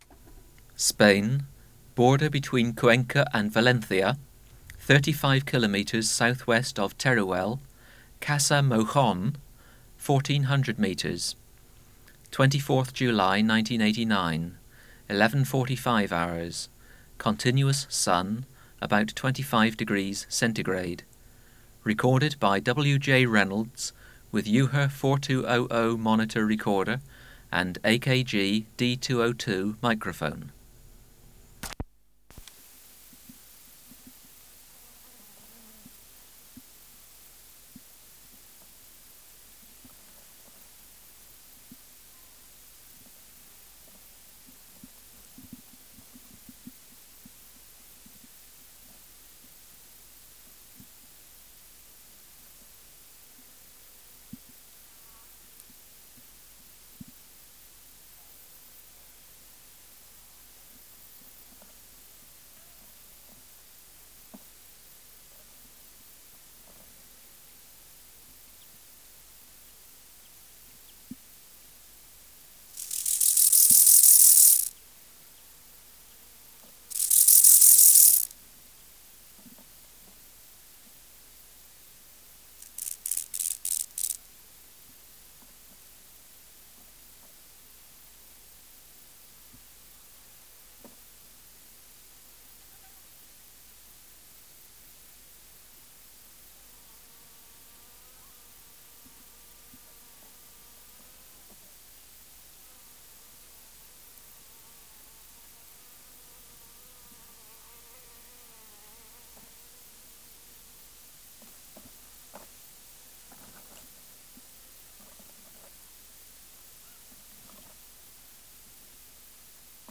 Species: Chorthippus (Glyptobothrus) yersini
Reference Signal: 1 kHz for 10 s
Air Movement: Slight breeze
Substrate/Cage: On herb
Microphone & Power Supply: AKG D202 (LF circuit off) Distance from Subject (cm): 7 Windshield: W 29